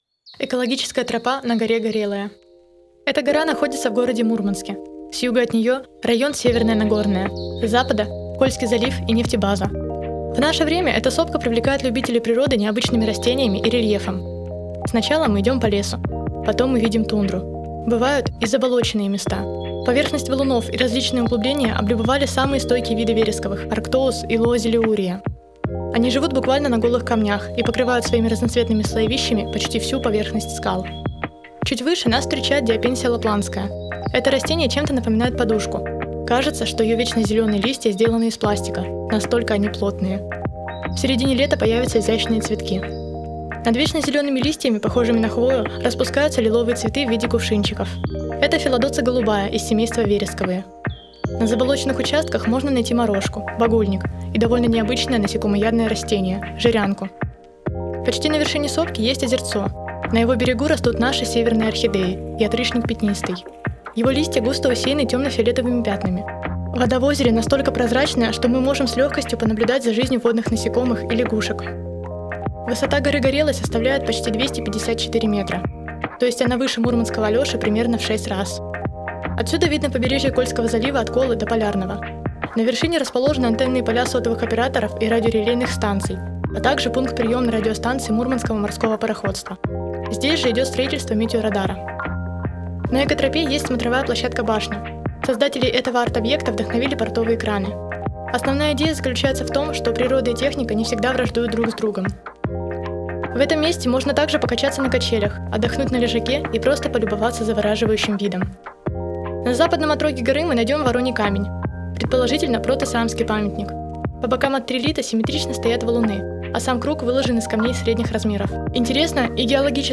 Новая аудиоэкскурсия проекта «51 история города М»!